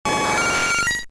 Cri de Roucarnage K.O. dans Pokémon Diamant et Perle.